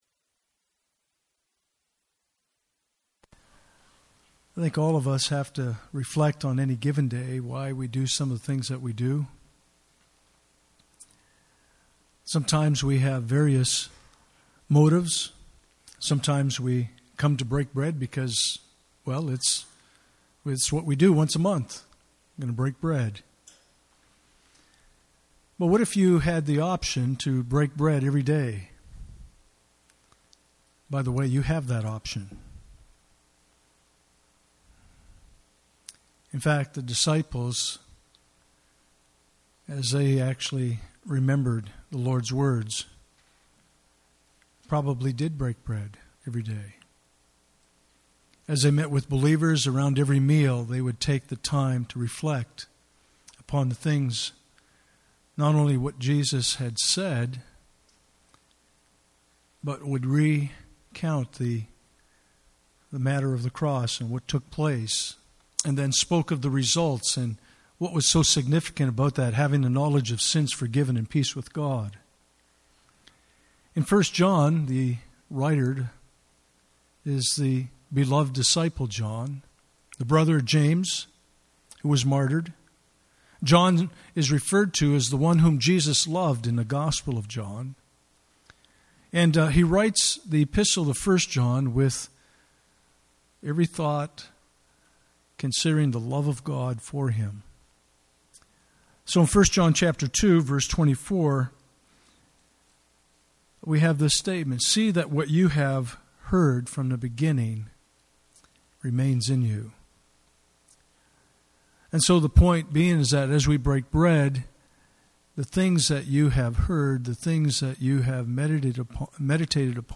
Communion Service
Passage: 1 Peter 1:13-23 Service Type: Sunday Morning